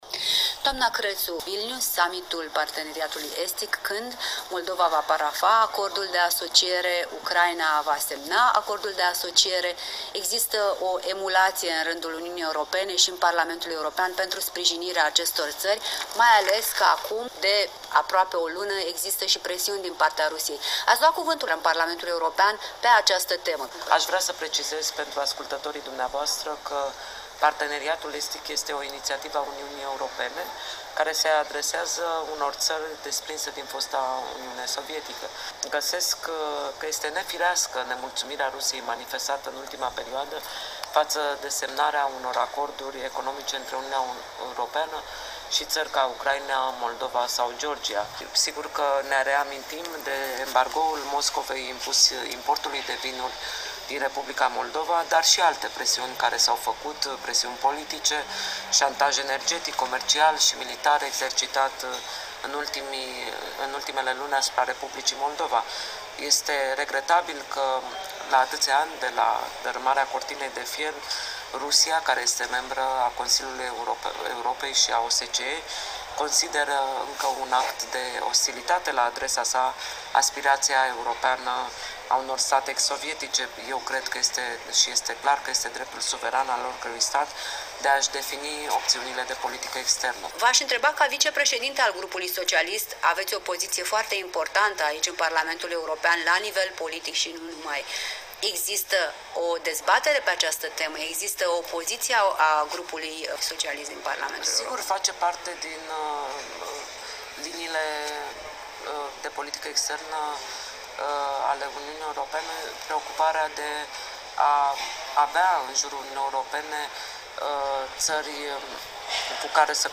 Un interviu în exclusivitate pentru Europa Liberă cu europarlamentara Corina Crețu